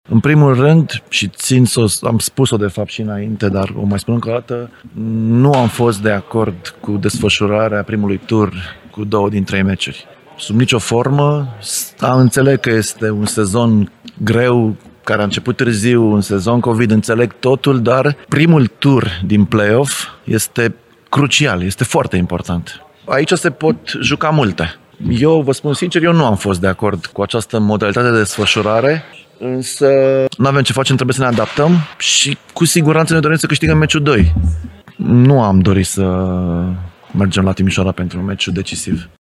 ORADEA-TIMISOARA-INTERVIEWS.mp3